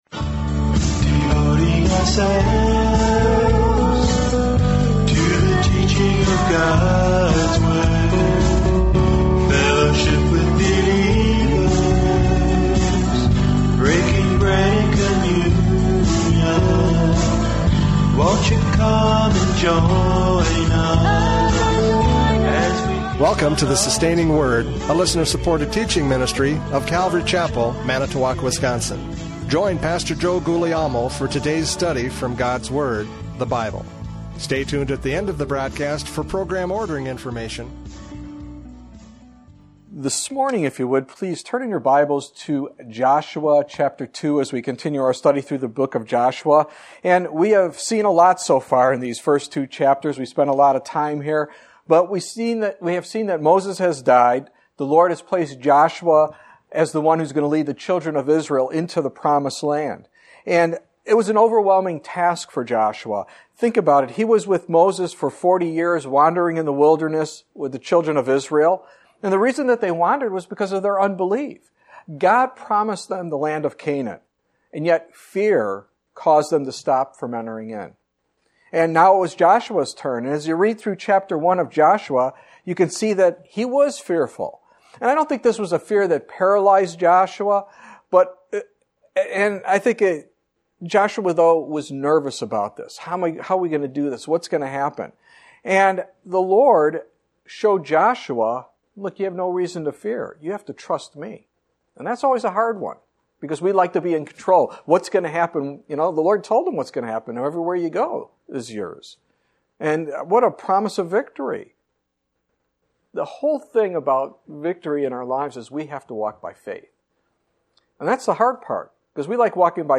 Joshua 2:22-24 Service Type: Radio Programs « Joshua 2:15-21 The Result of Rahab’s Faith!